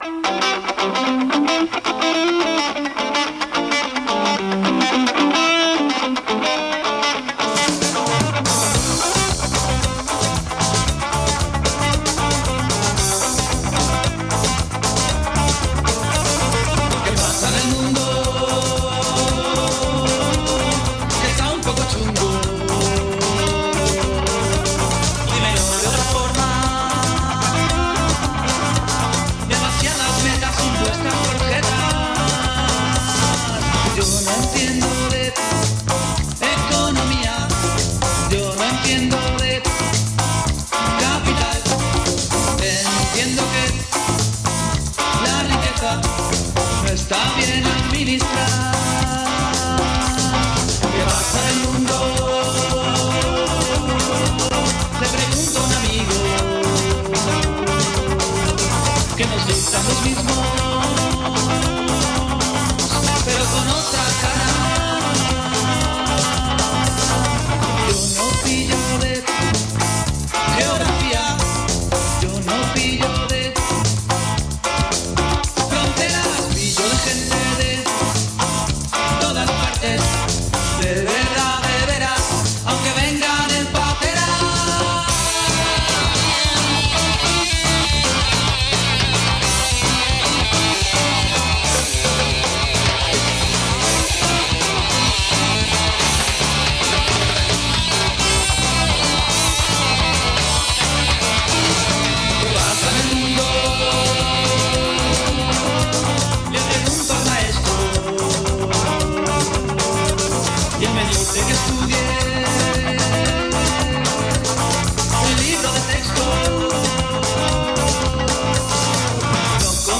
Repaso y tertulia alrededor de la últimas elecciones en Italia, otra farsa electoral, teniendo en cuenta que el FMI y el BCE, han advertido que sea cual sea la agrupación política que se haga cargo de el gobierno en Italia, las políticas de recortes y estrangulamiento económico y social, no van a ser modificadas.